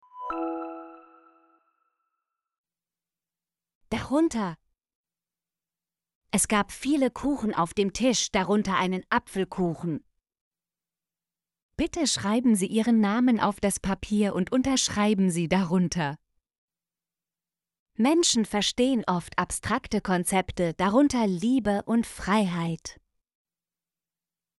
darunter - Example Sentences & Pronunciation, German Frequency List